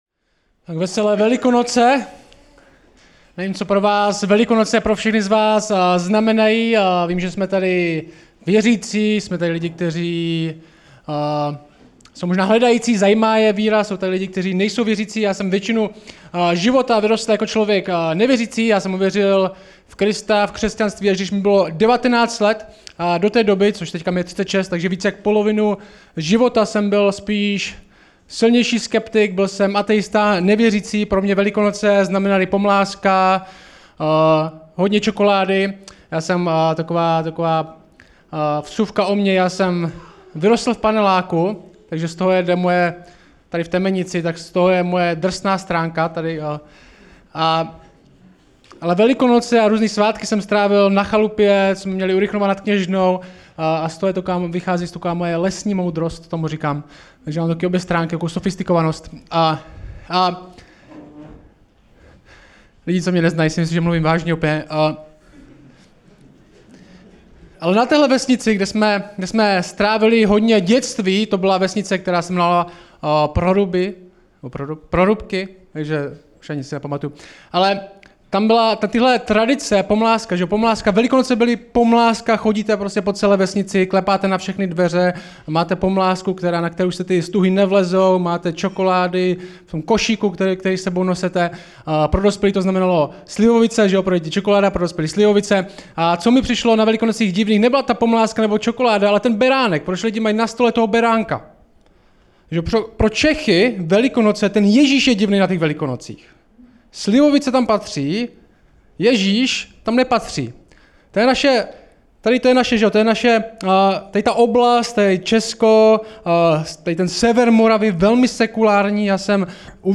Samostatná kázání